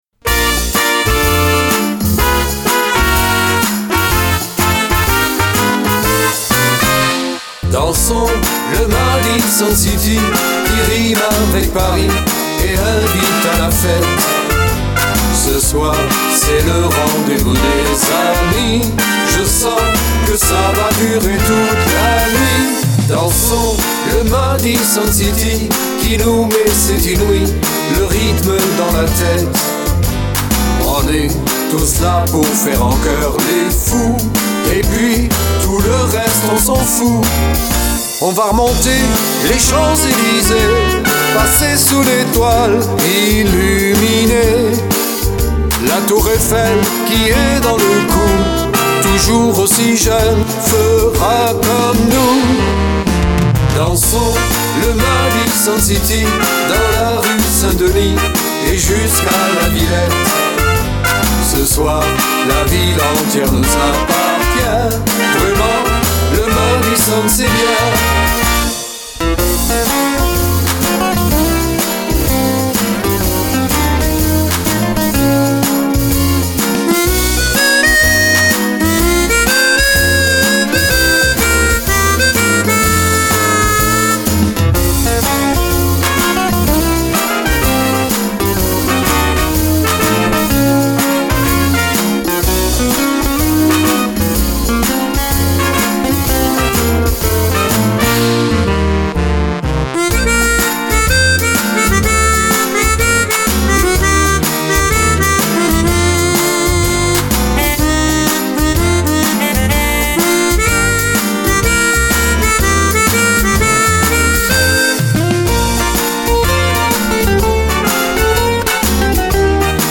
version chantée intégrale